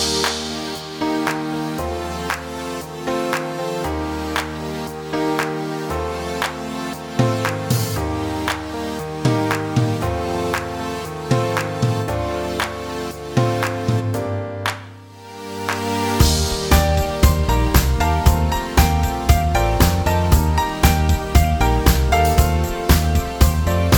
no Backing Vocals Pop (2010s) 3:29 Buy £1.50